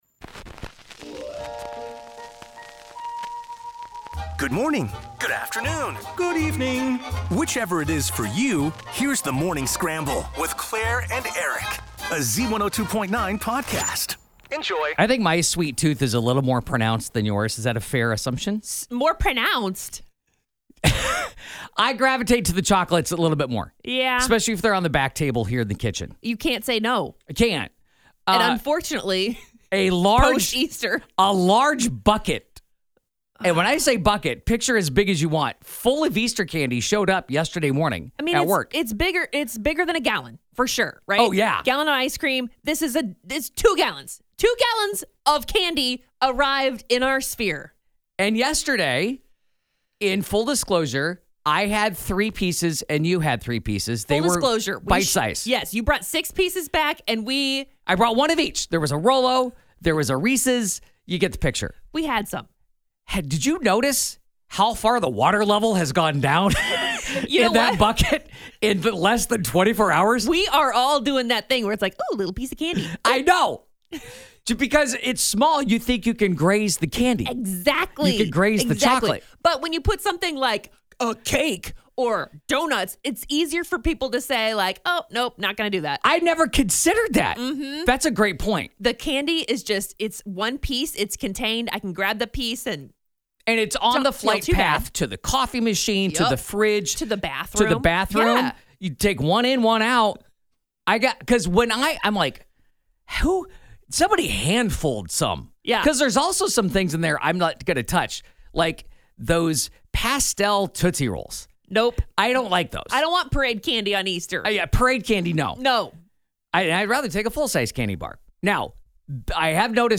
She just kicked off her ‘Taking Care of Biscuits Tour’ and gave The Morning Scramble a call from the road. Tune in to her what she had to say about the upcoming show and her many acting roles, including what it was like being in ‘Zootopia 2.’